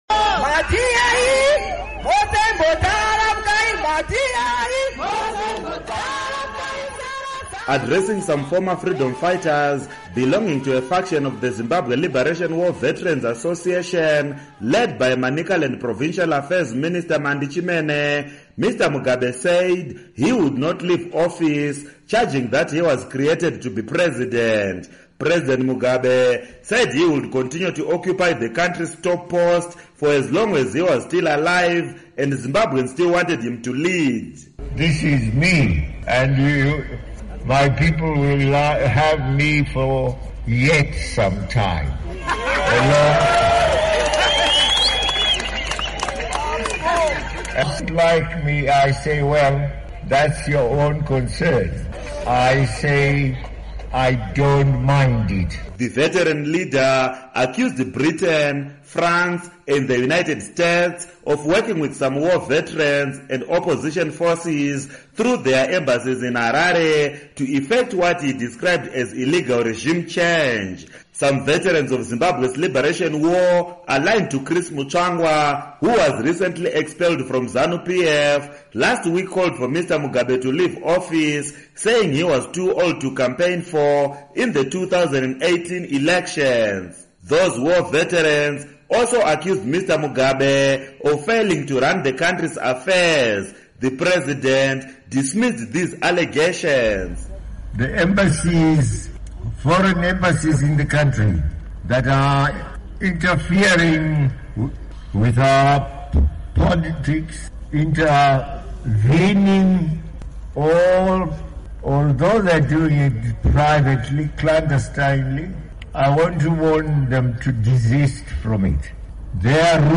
Report on War Veterans